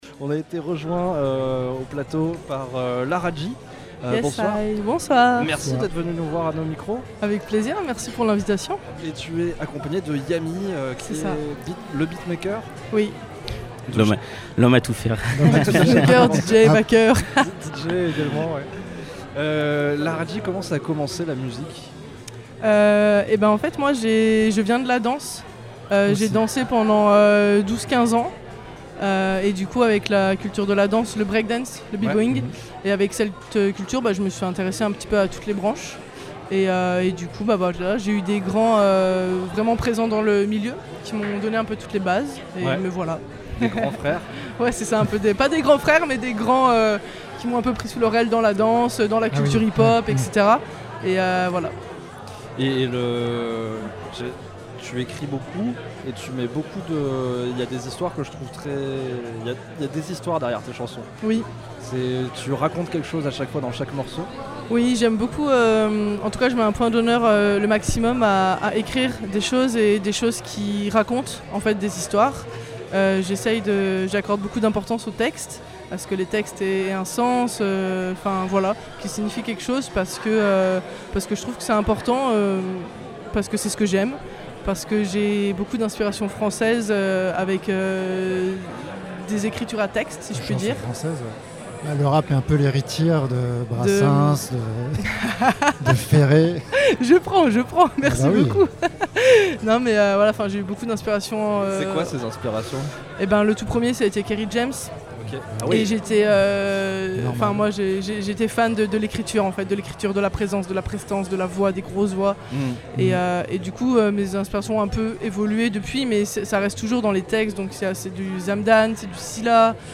Ampli Interview